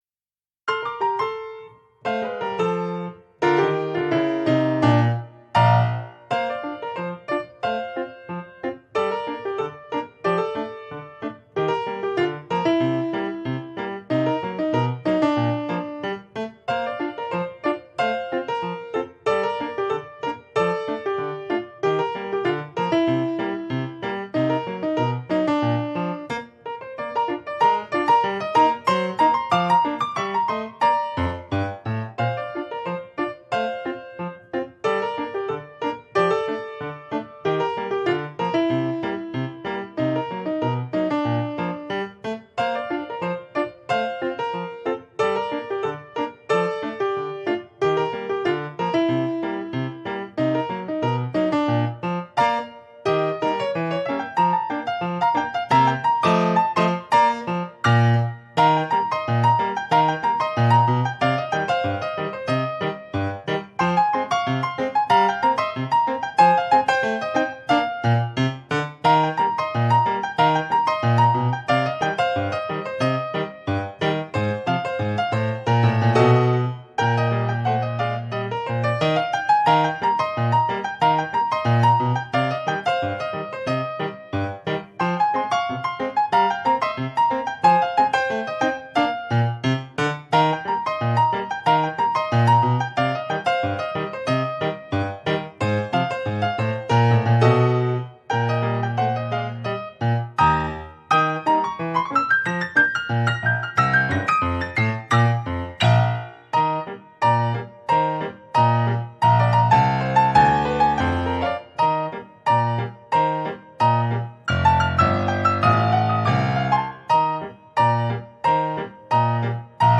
verbindet den klassischen Ragtime mit Elementen der Popmusik
verbindet typische Elemente des Ragtime